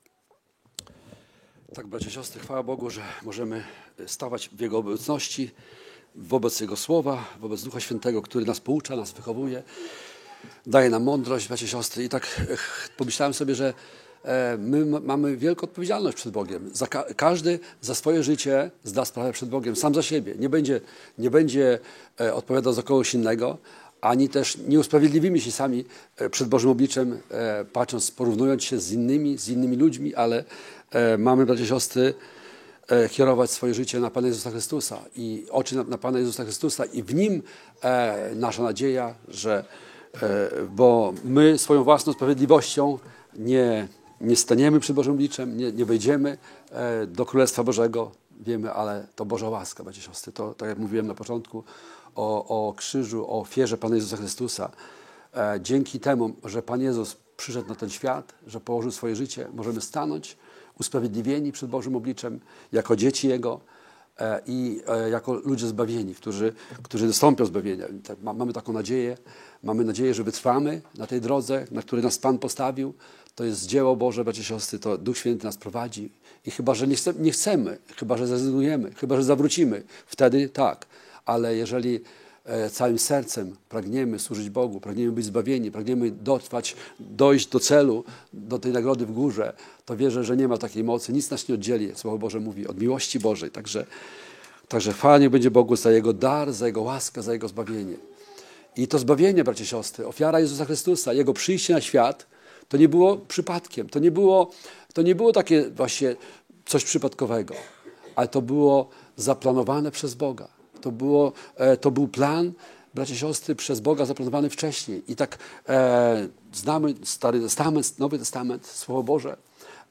Skorzystaj z przycisku poniżej, aby pobrać kazanie na swoje urządzenie i móc słuchać Słowa Bożego bez połączenia z internetem.